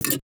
UIClick_Menu Back Metallic Double Click 01.wav